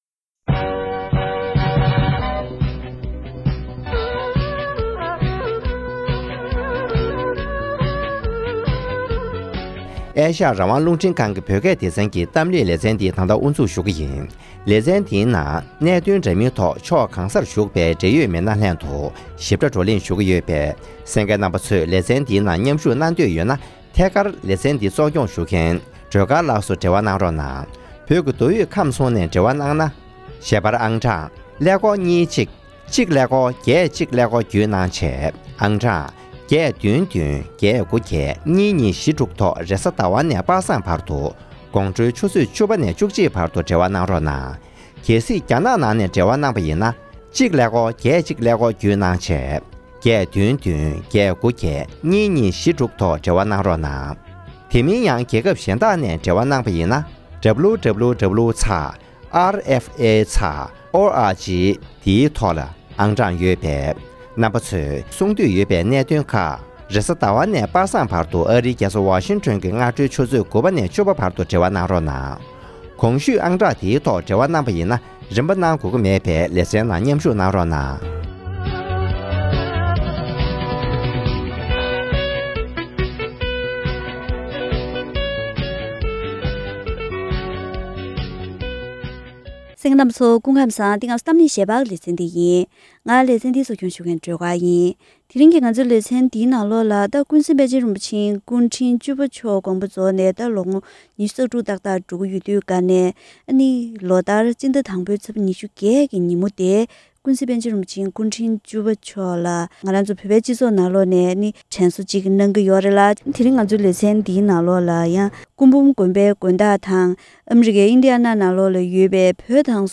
༄༅། །དེ་རིང་གི་གཏམ་གླེང་ཞལ་པར་ལེ་ཚན་འདིའི་ནང་ཀུན་གཟིགས་པཎ་ཆེན་རིན་པོ་ཆེ་སྐུ་ཕྲེང་བཅུ་པ་མཆོག་དགོངས་པ་རྫོགས་ནས་ལོ་ངོ་༢༦ ཕྱིན་པའི་སྐབས་དེར་ཨ་ཀྱཱ་རིན་པོ་ཆེར་པཎ་ཆེན་རིན་པོ་ཆེའི་མཛད་རྗེས་སྐོར་བཀའ་འདྲི་ཞུས་པ་ཞིག་གསན་རོགས་གནང་།།